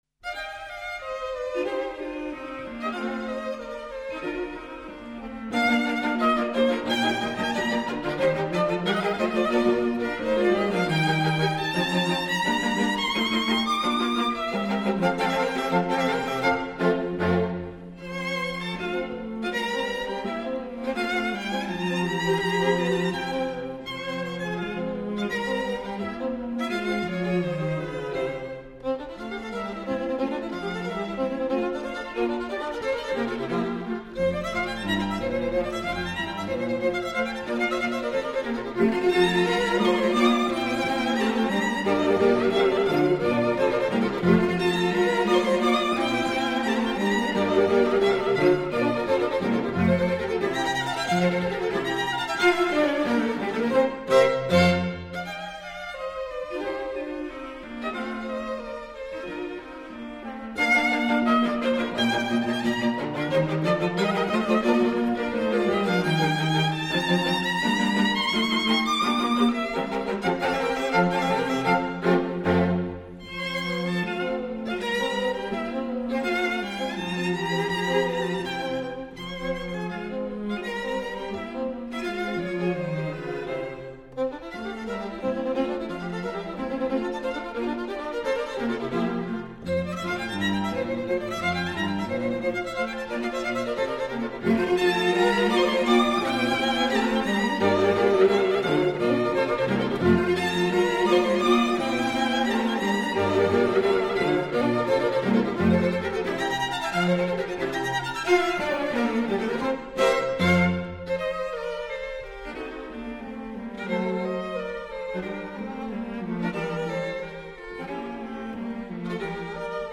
String Quartet in B flat major
Allegro assai